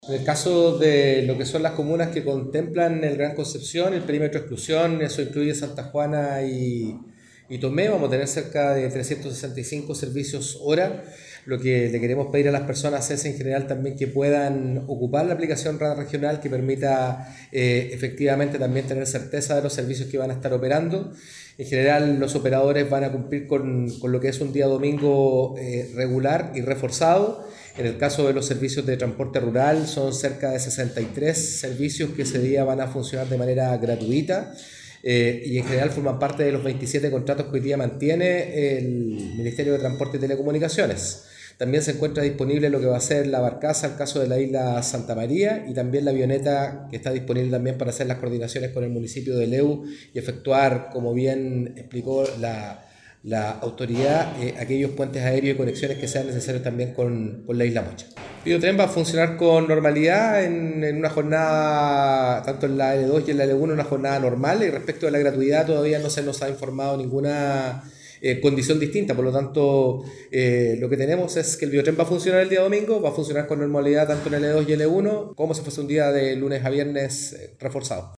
En el encuentro también participó el seremi de Transportes, Patricio Fierro, quien explicó el funcionamiento de las micros y del Biotren, indicando que habrá un incremento de la frecuencia cercano al 10%, en comparación a un domingo regular.